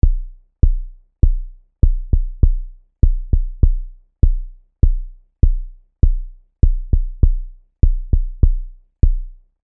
Hier die TB 606 der PunchBox:
Diese bietet erwartungsgemäß die wenigsten Variationen beim Sound.
Immerhin ist die PunchBox-TB606 anschlagsdynamisch, was einen simplen Klopfer wie im letzten Audiodemo lebendig macht.